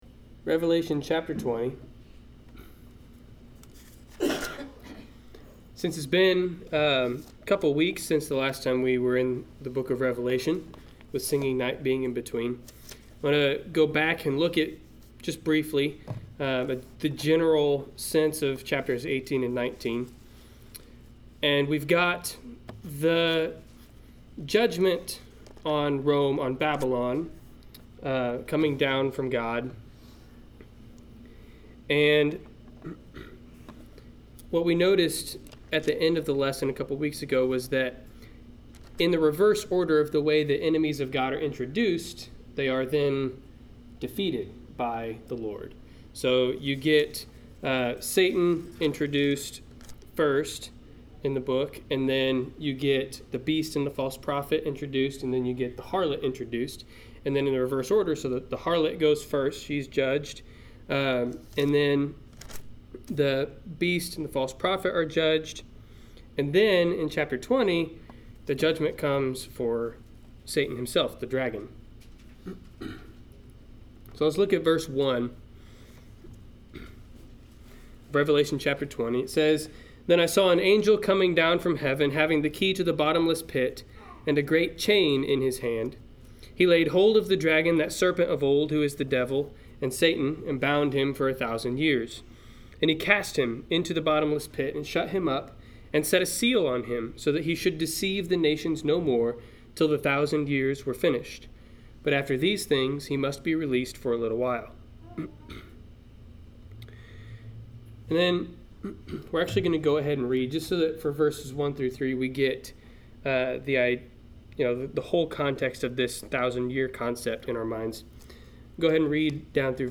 Service Type: Wednesday Night Class